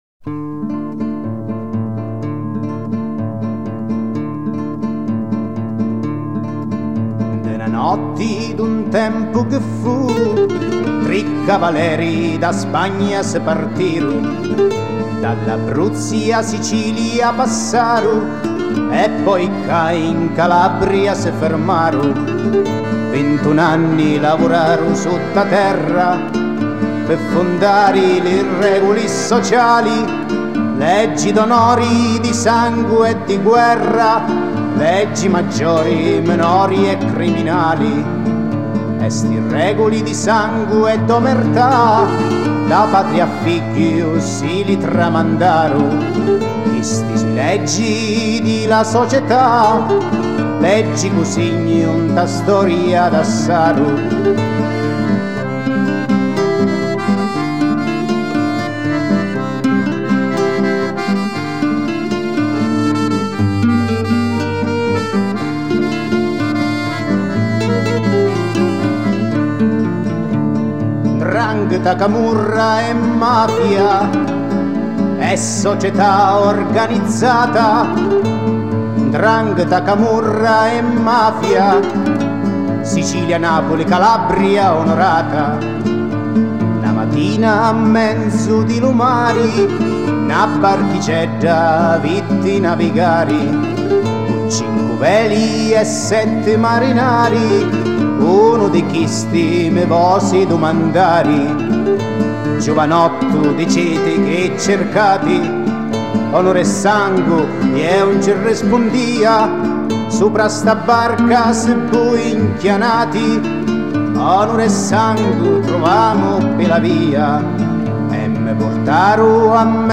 Style: Folk